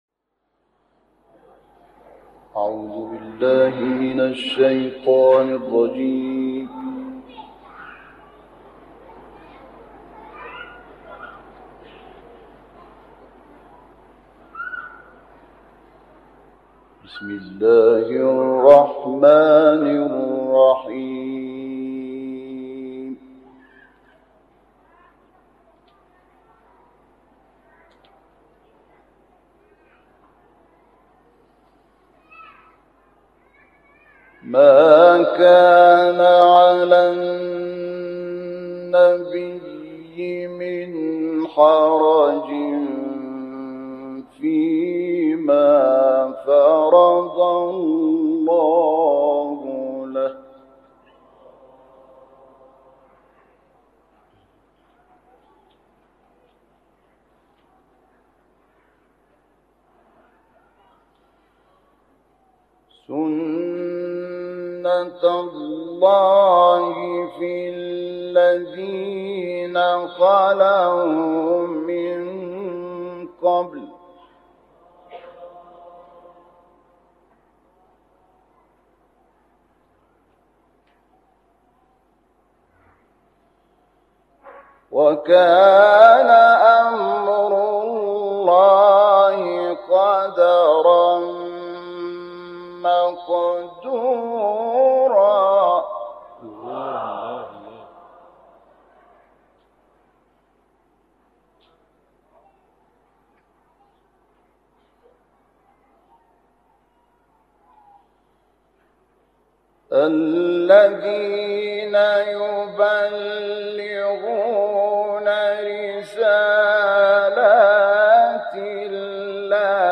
به مناسبت هفته وحدت و سالروز میلاد پیامبر مهربانی، تلاوت آیات ۳۸ تا ۴۸ سوره مبارکه احزاب را با صدای محمد شحات انور، قاری فقید مصری می‌شنوید. این تلاوت ماندگار سال ۱۳۶۹ در حسینیه ارشاد تهران اجرا شده است.